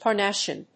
音節Par・nas・si・an 発音記号・読み方
/pɑɚnˈæsiən(米国英語)/